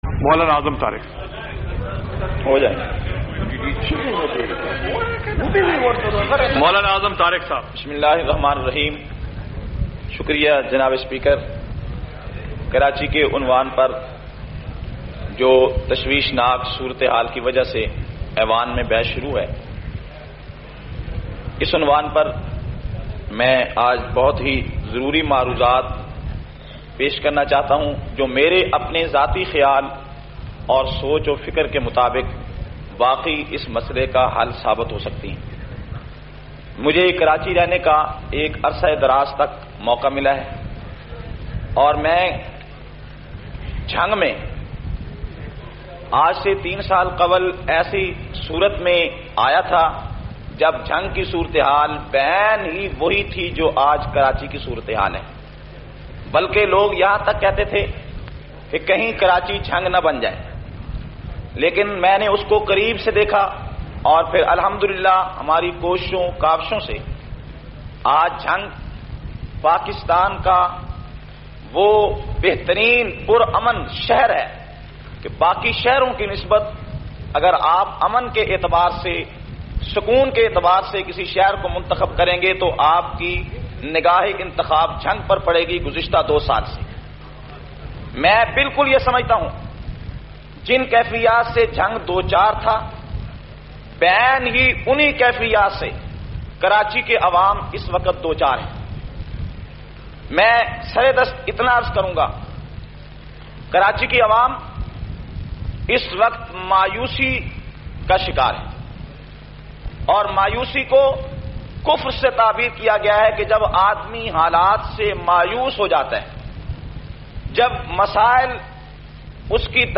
454- Qaomi Assembly Khutbat Vol 8.mp3